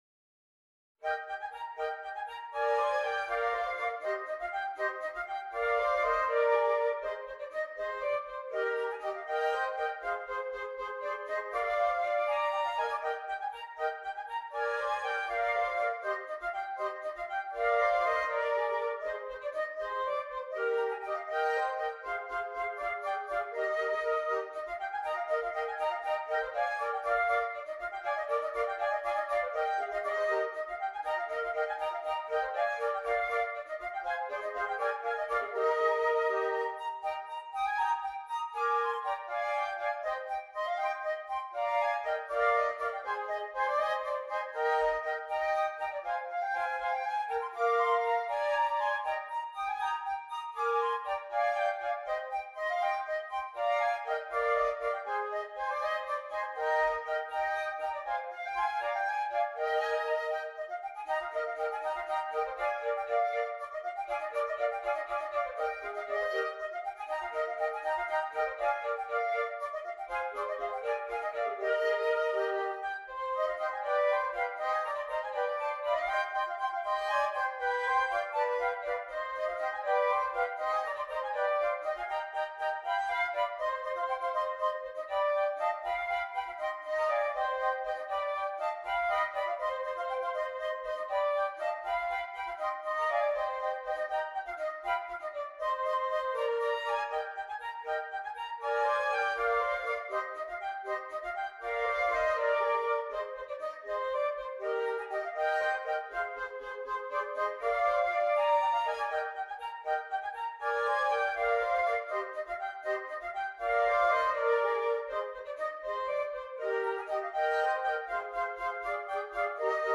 3 Flutes